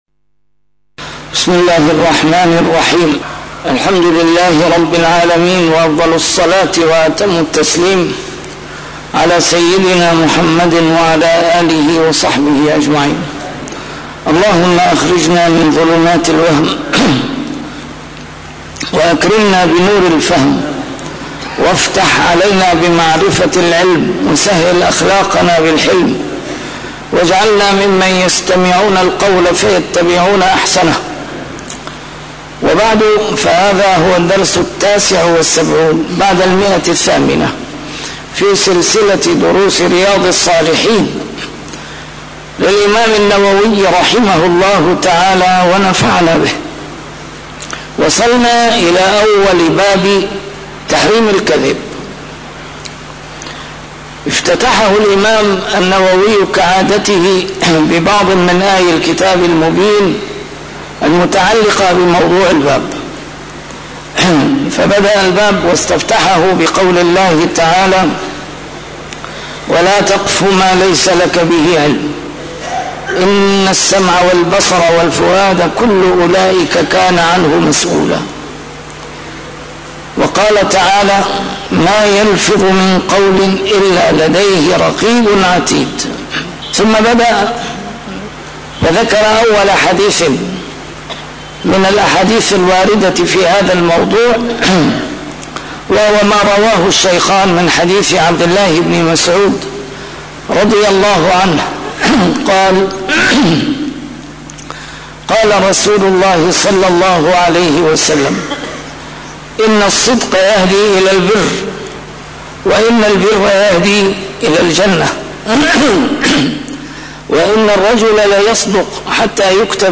A MARTYR SCHOLAR: IMAM MUHAMMAD SAEED RAMADAN AL-BOUTI - الدروس العلمية - شرح كتاب رياض الصالحين - 879- شرح رياض الصالحين: تحريم الكذب